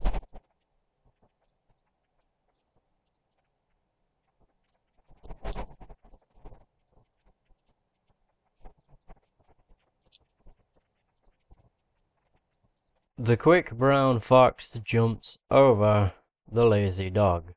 I’m trying to record commentary, the equipment i’m using is the Sony ECM-CS3 Condenser Mic.
I have 2 audio samples, one before applying noise removal & one after applying noise removal, both clips have been normalised.
I am not sure what your complaint is from reading, but from listening, the problem seems to be that some click sounds in “before” now have an audible whooshing noise in them “after.” Is that it?